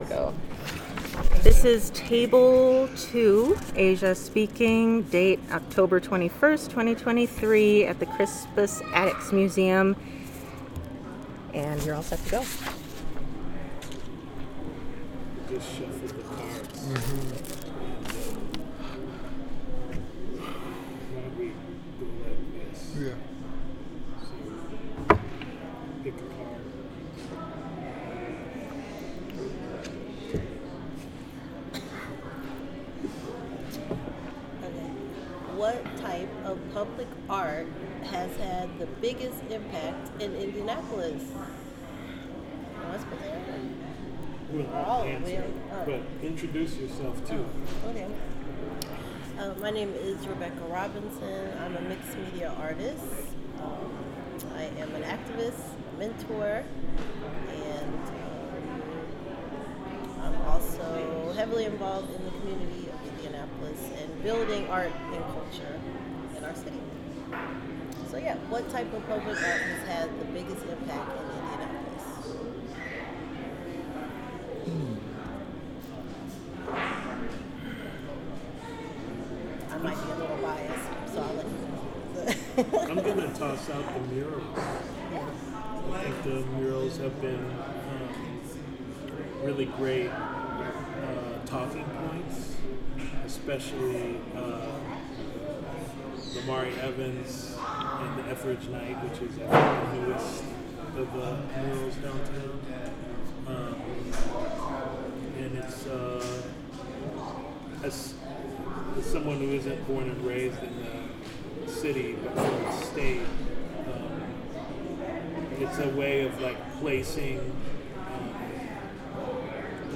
sound recording-nonmusical
oral history